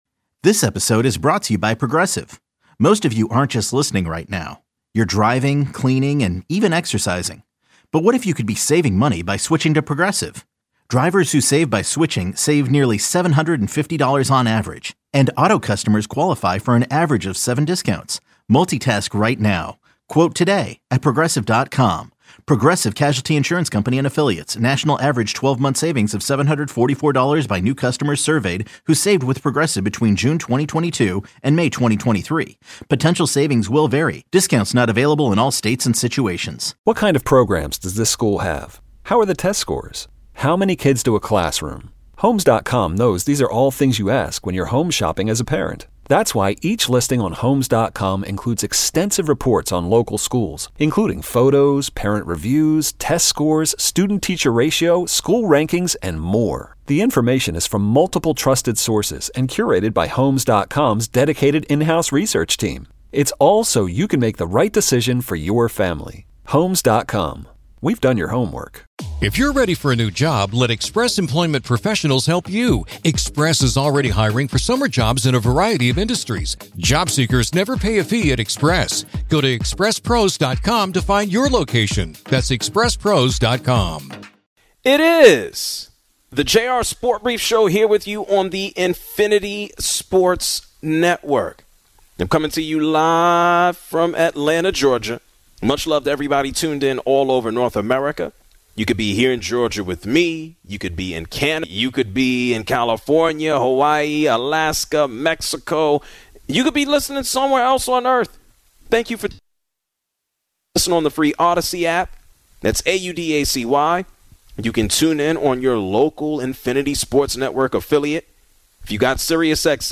Calls on hall of fame criteria